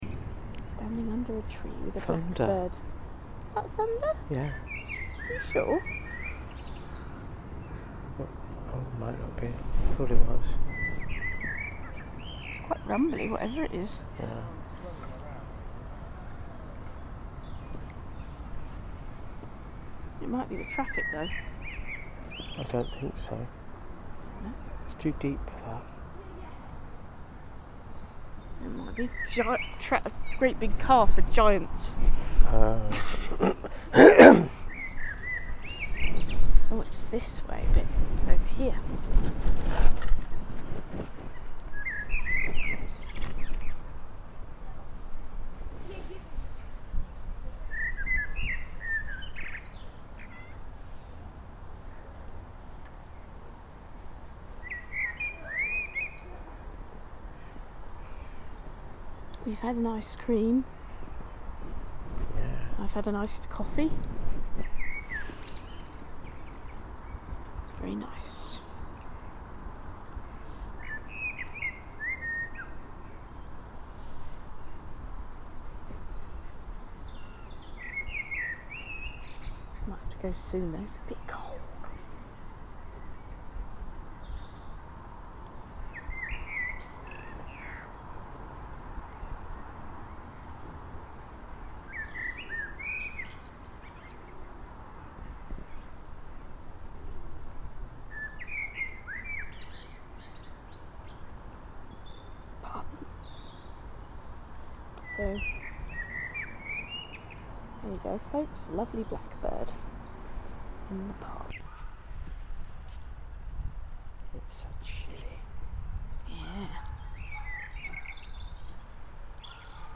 Birds in the park 20 May 2025
A stereo recording of birds in the park, including ducks, geese and moorhens on the lake. Use headphones or good speakers for the best listening experience.